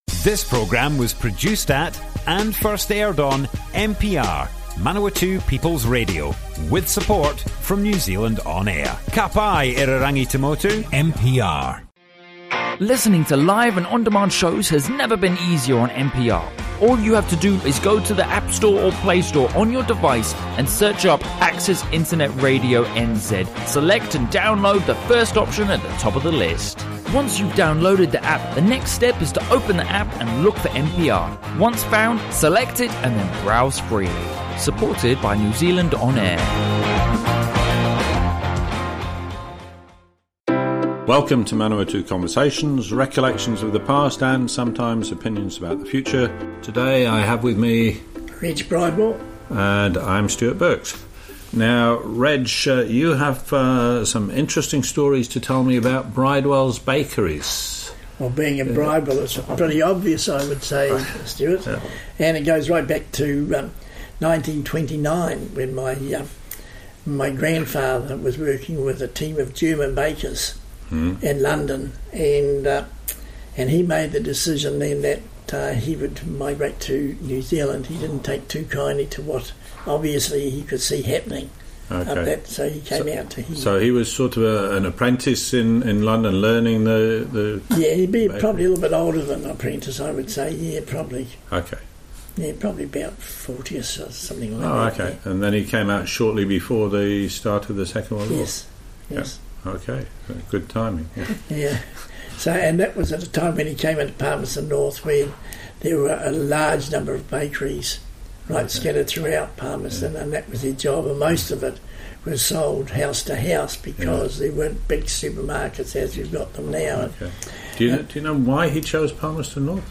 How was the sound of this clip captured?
Broadcast on Manawatu People's Radio 8 January 2019.